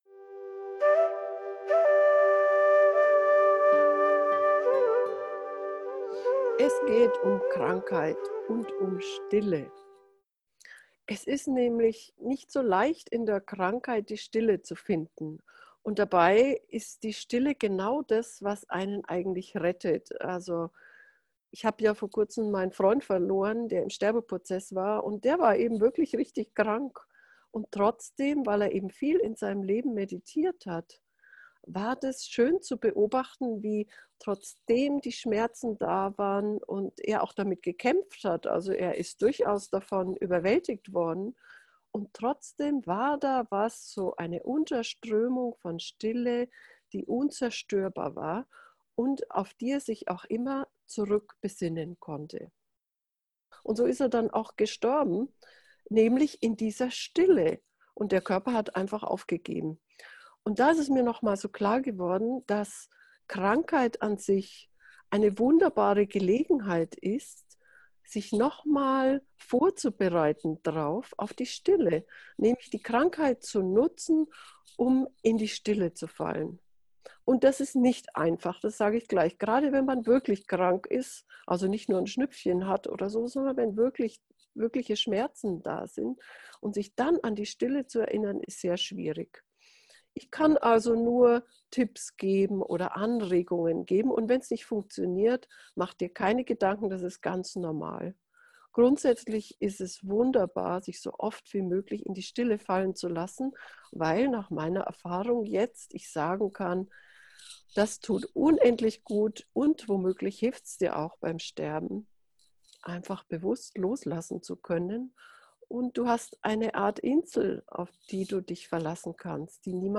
krankheit-schmerzen-stille-gefuehrte-meditation